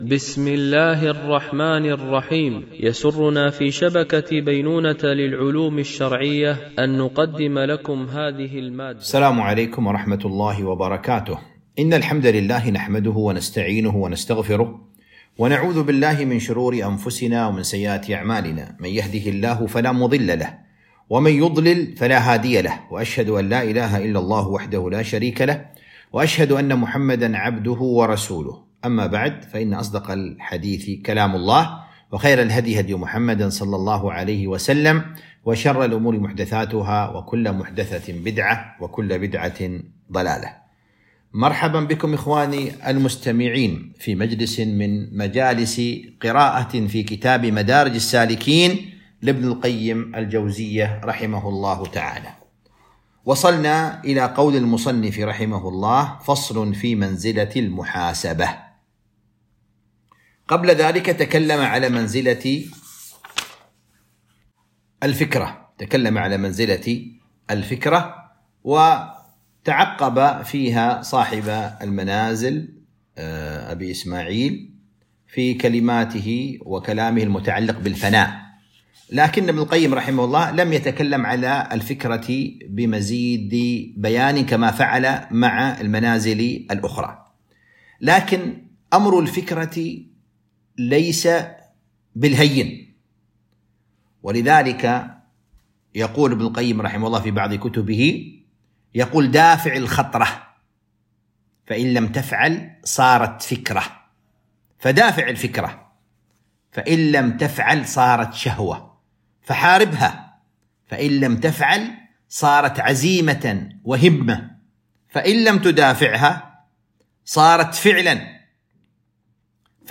قراءة من كتاب مدارج السالكين - الدرس 21